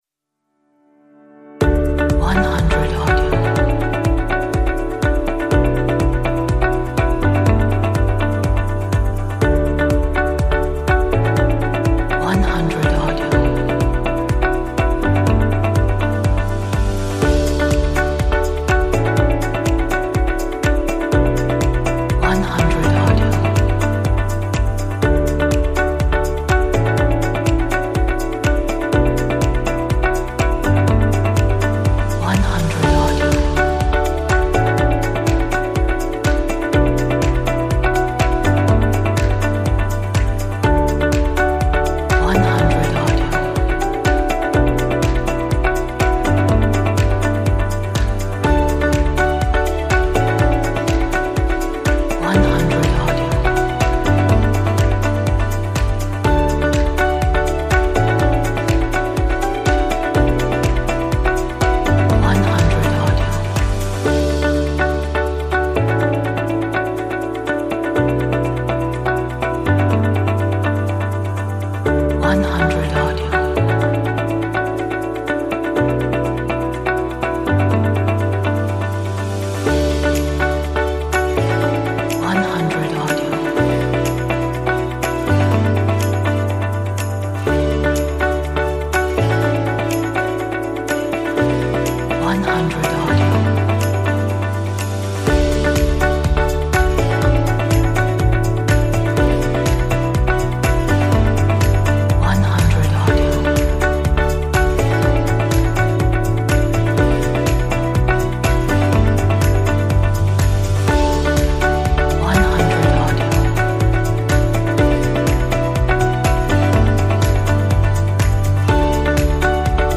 Positive Background Music.